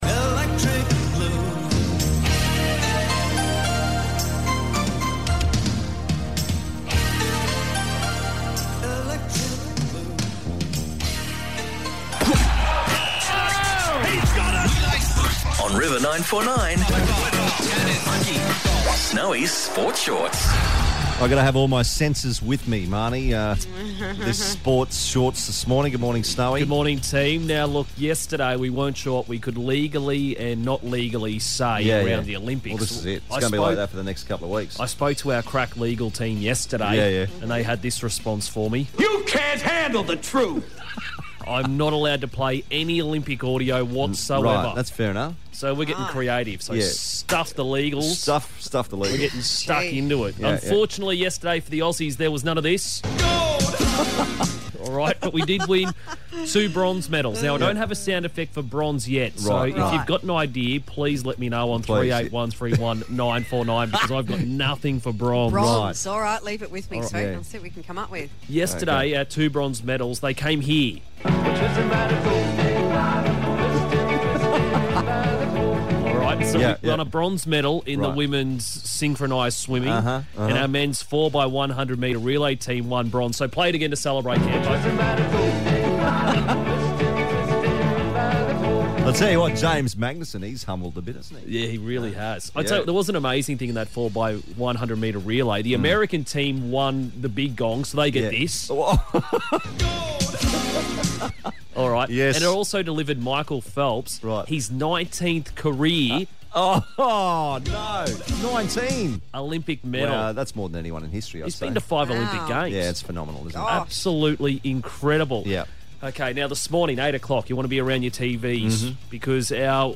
What happens when you're not allowed to use Olympic Games audio in a sports report..?